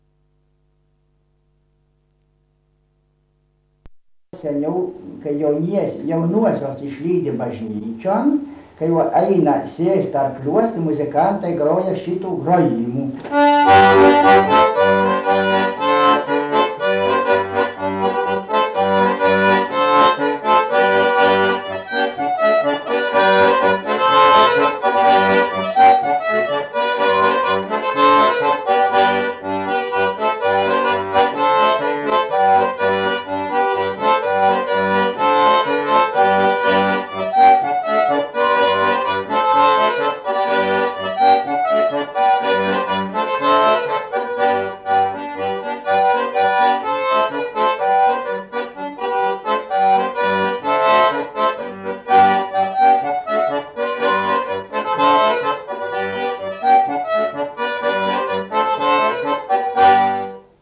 Maršas